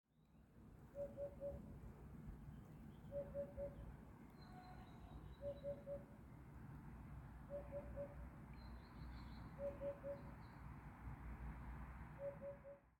Птицы -> Удод ->
удод, Upupa epops
Administratīvā teritorijaOgres novads